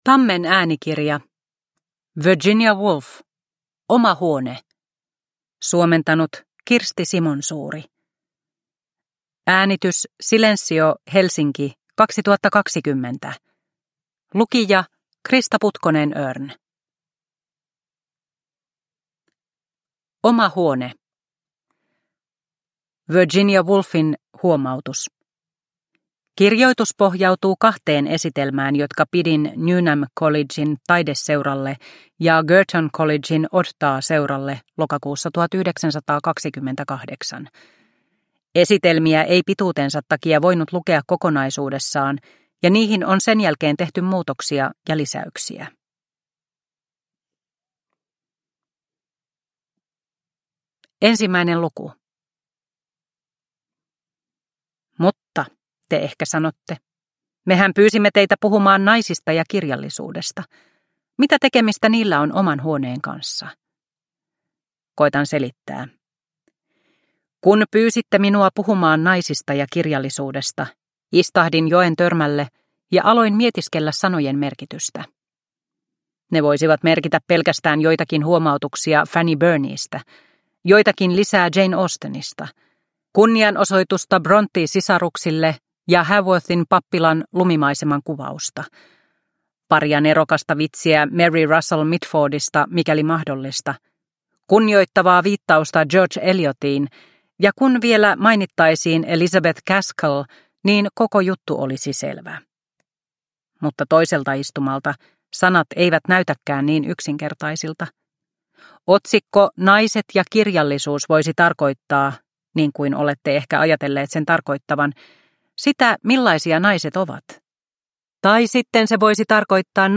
Oma huone – Ljudbok – Laddas ner